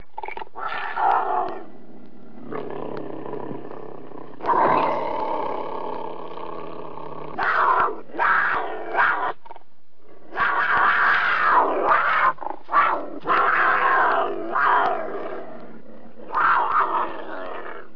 دانلود آهنگ حیوانات جنگلی 40 از افکت صوتی انسان و موجودات زنده
دانلود صدای حیوانات جنگلی 40 از ساعد نیوز با لینک مستقیم و کیفیت بالا
جلوه های صوتی